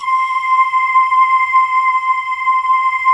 Index of /90_sSampleCDs/USB Soundscan vol.28 - Choir Acoustic & Synth [AKAI] 1CD/Partition D/03-PANKALE